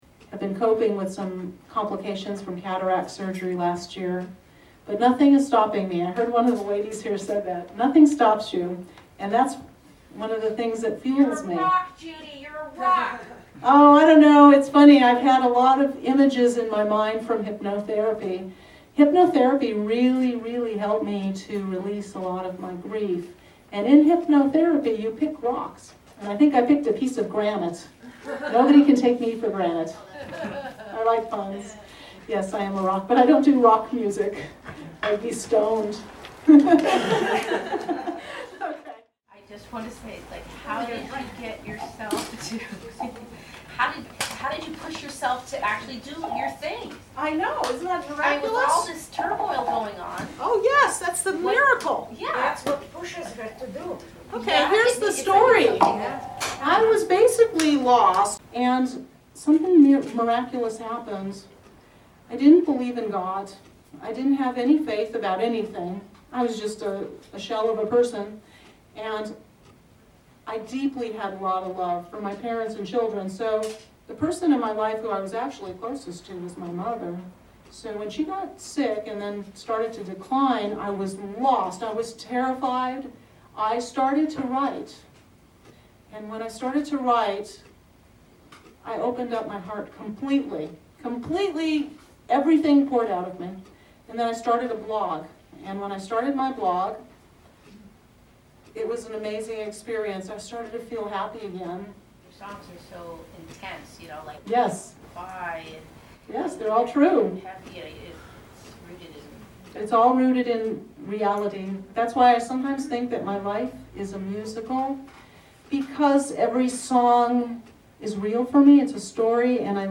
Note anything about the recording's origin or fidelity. Below is a 2-minute audio excerpt from one of my recent shows.